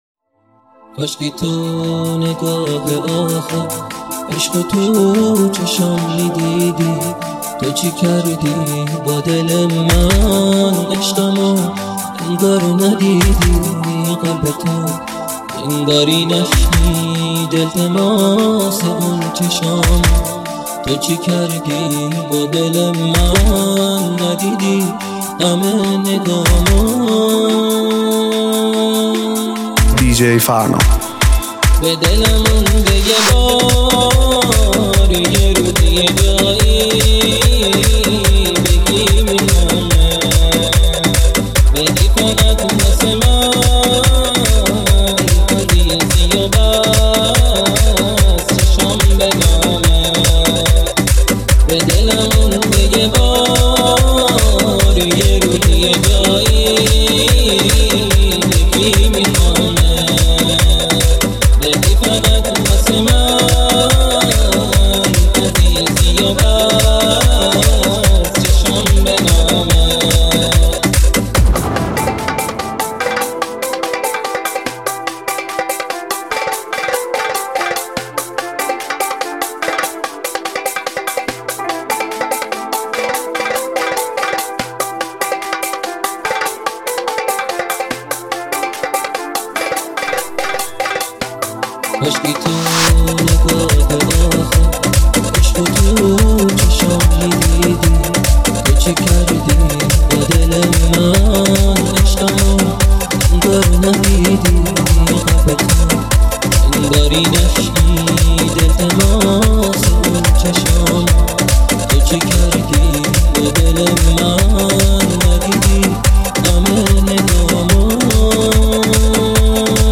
(بیس دار)